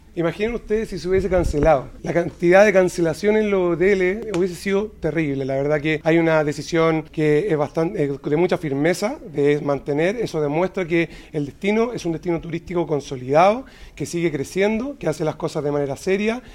El director regional de Sernatur, Juan Pablo Leiva, valoró la decisión de mantener el evento por las consecuencias que habría generado su suspensión.